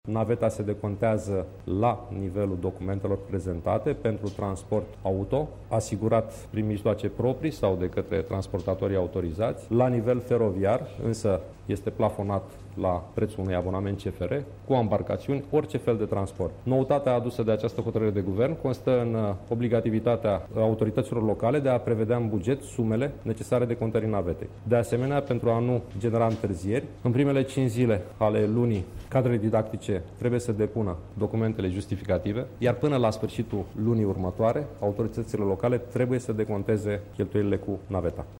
Ministrul Educaţiei, Sorin Câmpeanu: